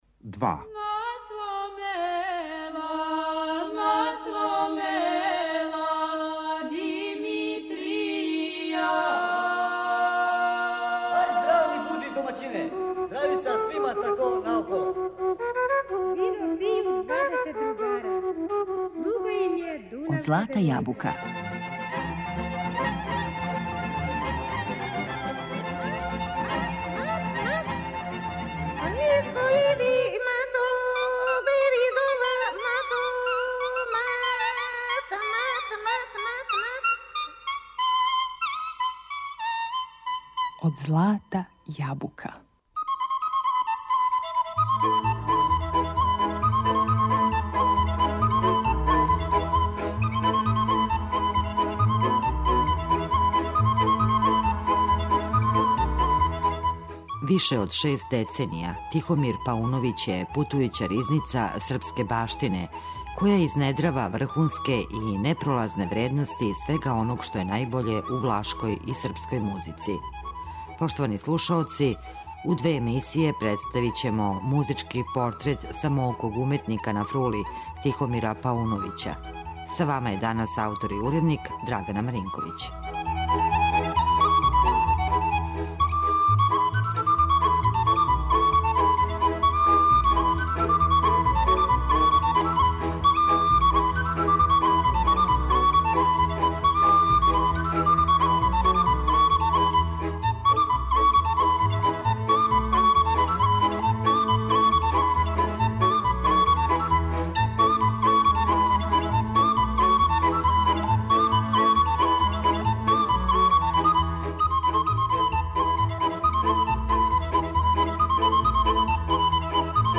Више од шест деценија је путујућа ризница српске баштине, која је изнедрила оно што је најбоље у влашкој и српској музици.